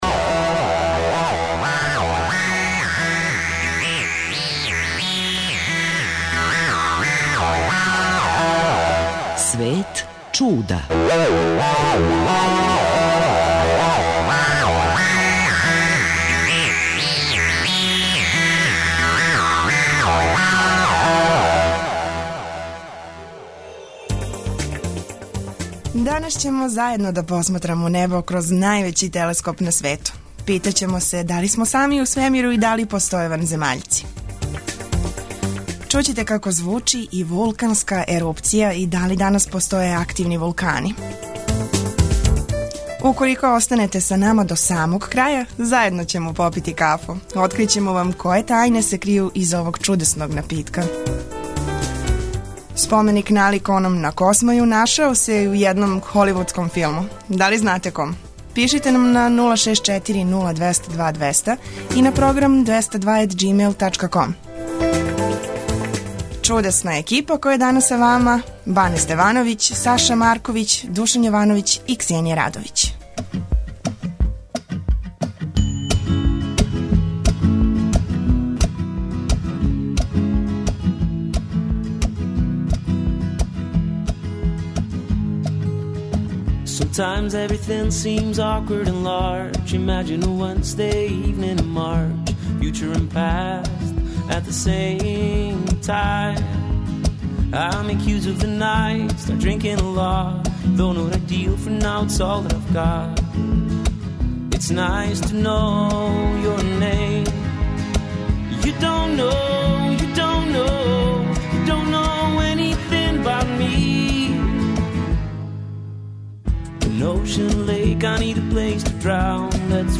Чућете како звучи вулканска ерупција и да ли и данас постоје активни вулкани.